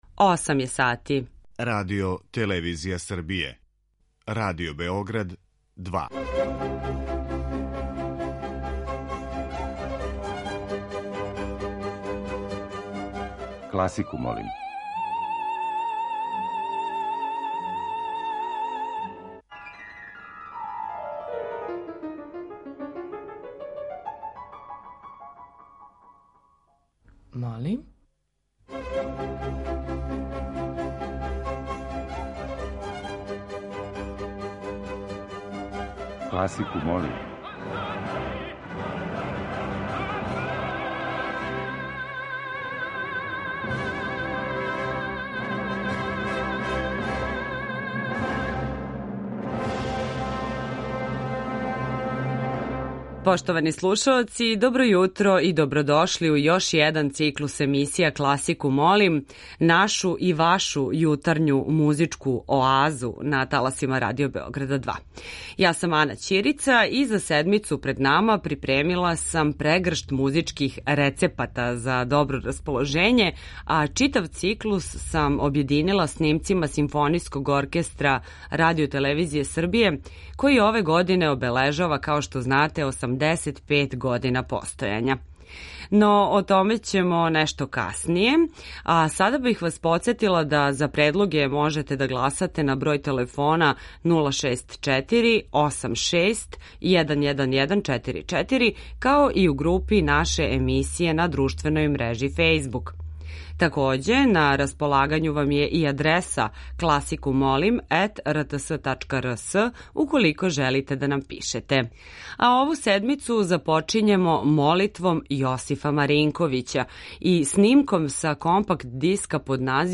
У години када оркестар наше куће прославља 85 година постојања, последњи јунски циклус емисија Класику, молим! биће обједињен овогодишњим снимцима са концерата Симфонијског оркестра РТС.